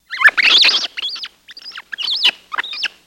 Rat Fearful Squeak